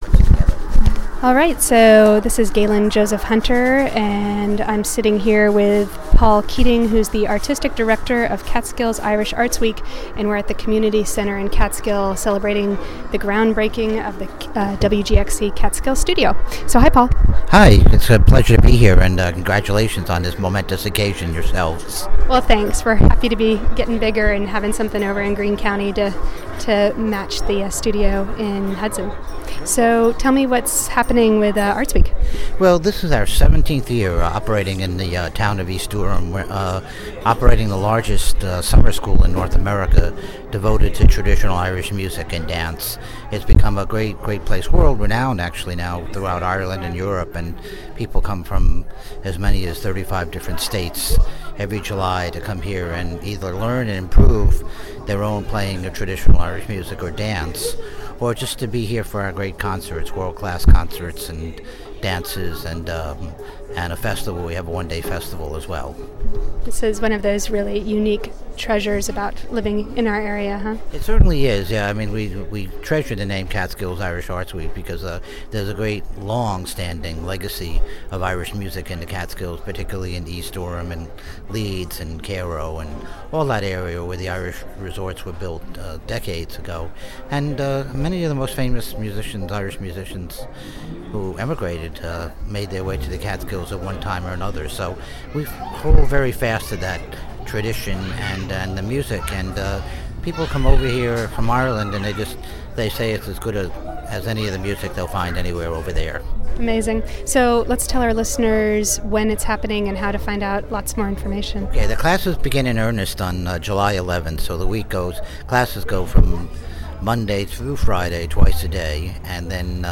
Interview
during the station's Catskill Studio groundbreaking event